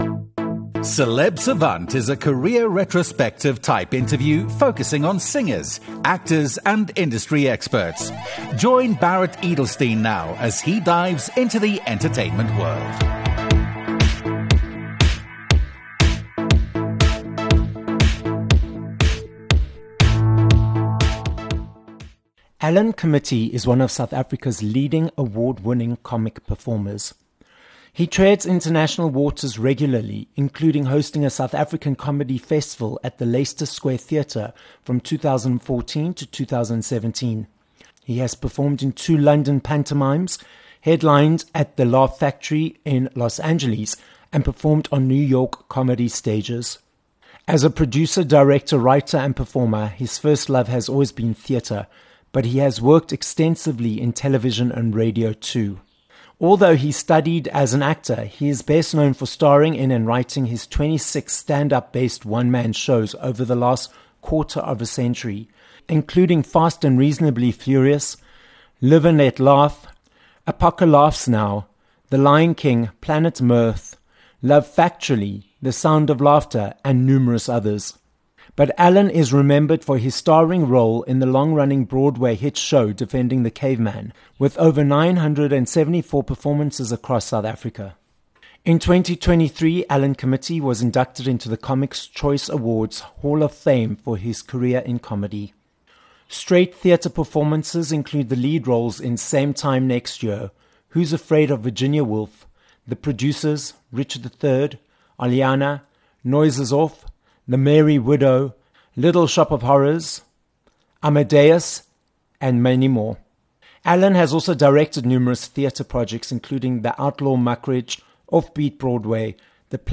26 Mar Interview with Alan Committie
Comedy takes centre stage on this episode of Celeb Savant, as we are joined by South African comedian, Alan Committie. Alan tells us how he was inspired by the clowns in the circus to follow a career in the entertainment industry, how he started off teaching to supplement the beginning of his career, and how he has built a brand of his name by creating new shows every year.